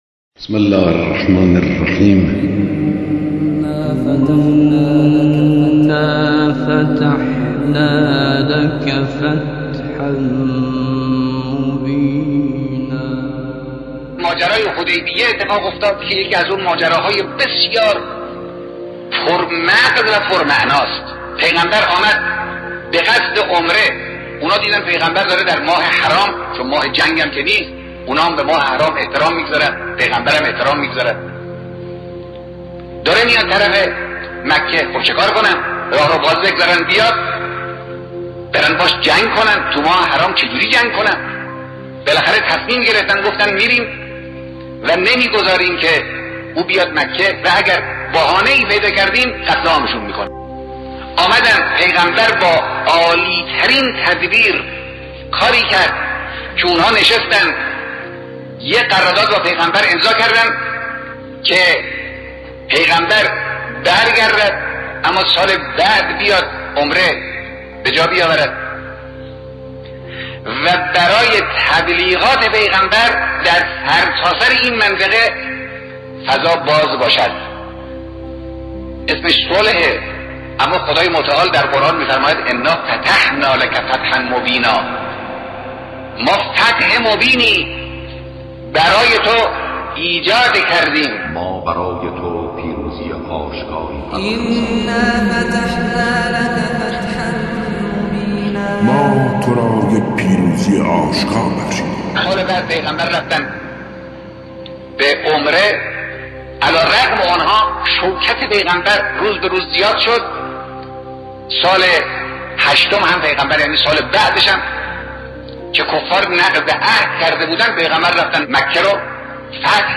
مدرسه قرآنی طه در این پادپخش، از بیانات آرشیوی اساتید برجسته قرآن کریم همچون مقام معظم رهبری و حجت‌الاسلام والمسلمین محسن قرائتی استفاده کرده است.